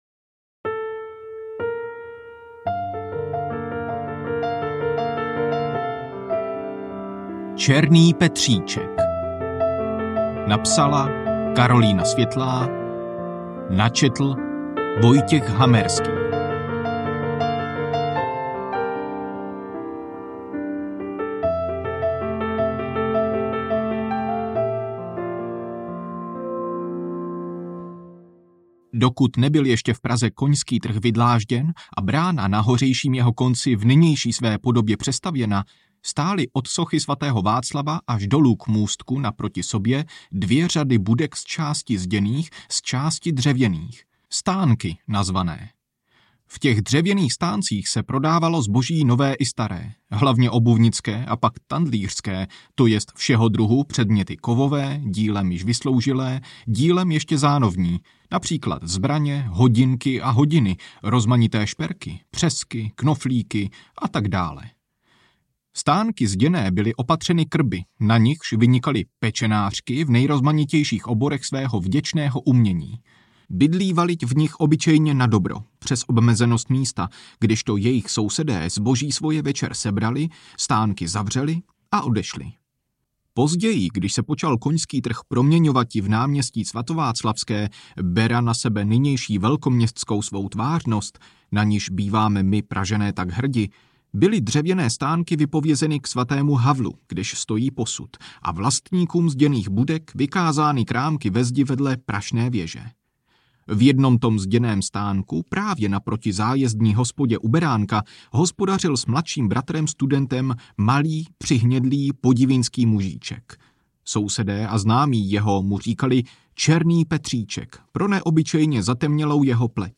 Černý Petříček audiokniha
Ukázka z knihy